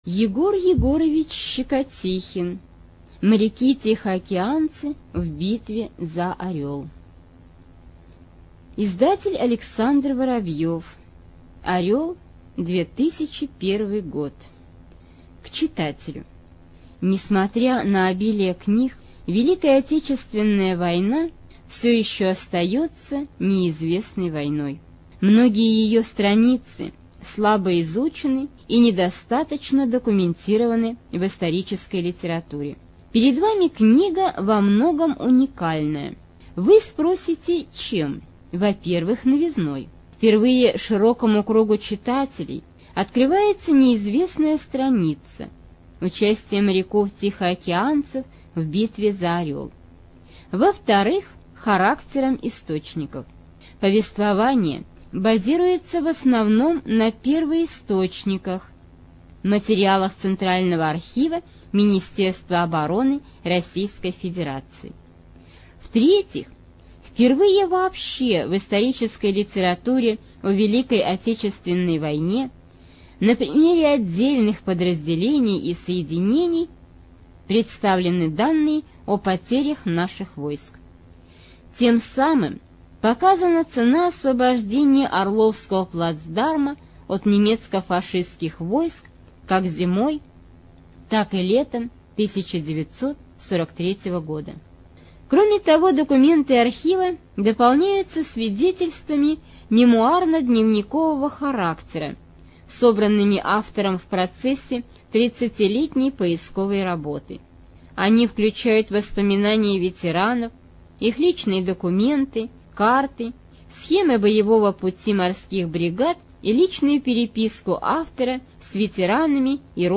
Студия звукозаписиОрловская областная библиотека для слепых